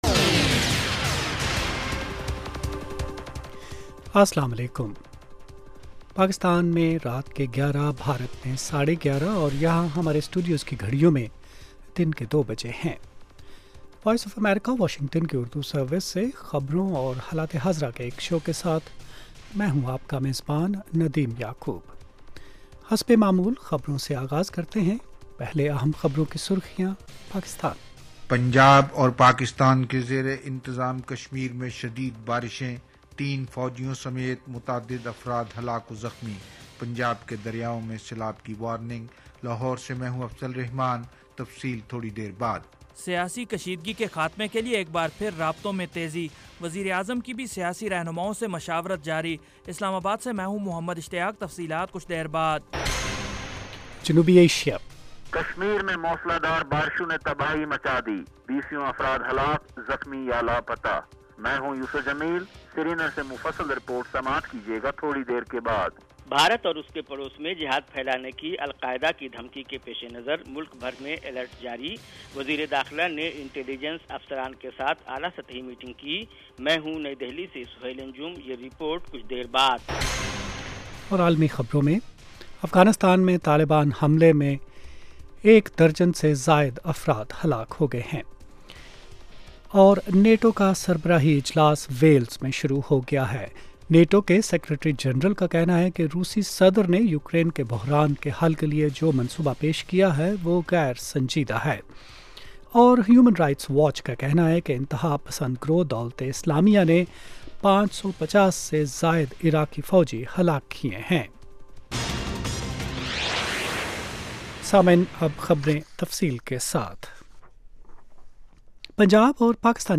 11:00PM اردو نیوز شو